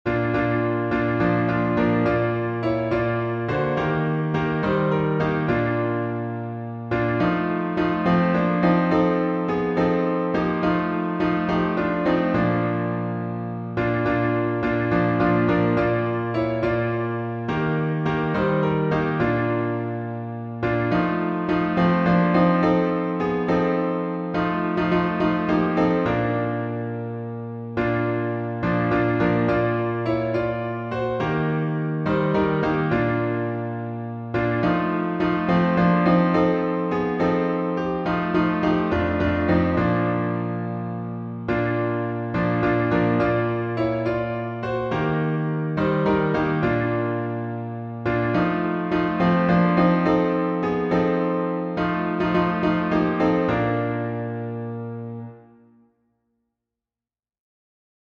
Words and music by unknown author Key signature: B flat major (2 flats) Time signature: 6/8 Meter: 7.6.7.7.8.7.